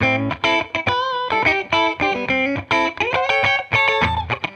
Index of /musicradar/sampled-funk-soul-samples/105bpm/Guitar
SSF_TeleGuitarProc2_105A.wav